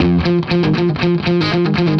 Index of /musicradar/80s-heat-samples/120bpm
AM_HeroGuitar_120-F01.wav